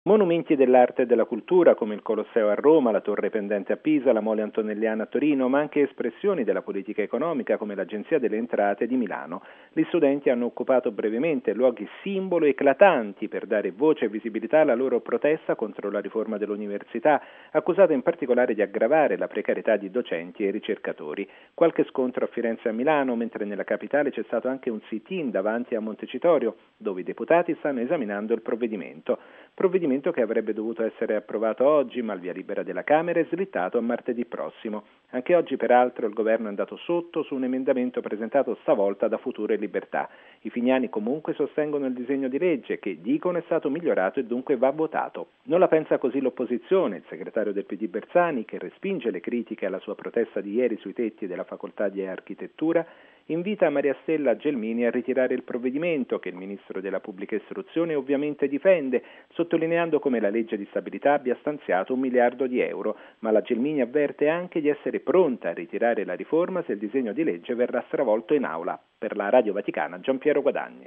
Dopo le manifestazioni e gli scontri di ieri, si intensificano in tutta Italia, anche utilizzando forme clamorose, le proteste di studenti e ricercatori contro la riforma dell’Università. La cui approvazione, prevista per oggi alla Camera, slitta a martedì prossimo. Il servizio